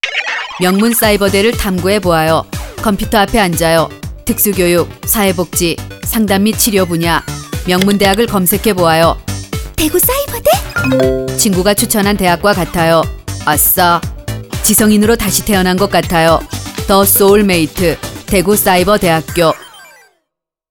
라디오 CM